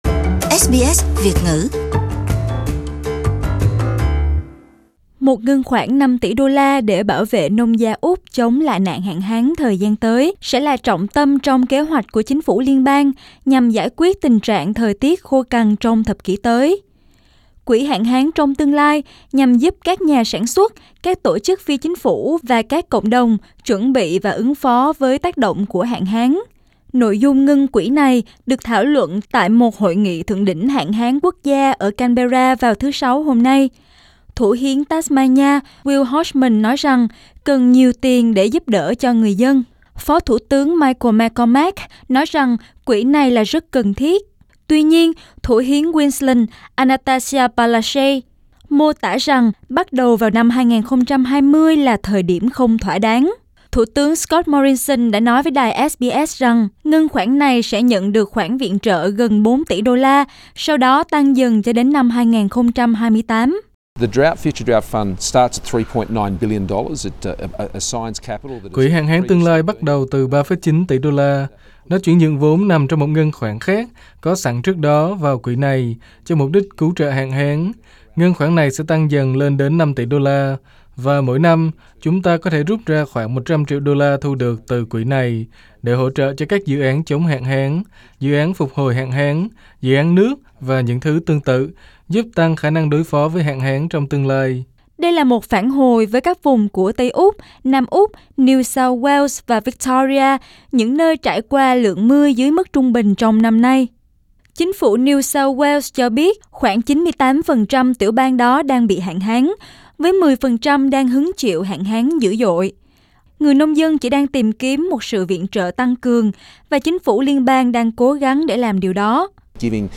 Prime Minister Scott Morrison in an interview with SBS Source: SBS